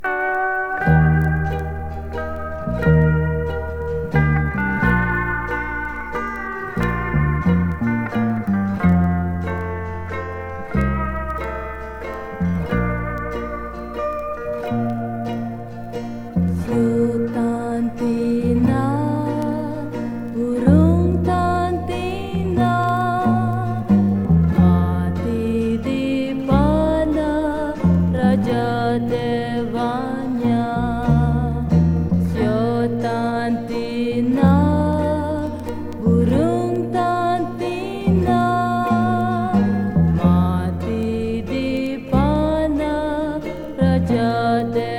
World, Folk, Krontjong　Netherlands　12inchレコード　33rpm　Stereo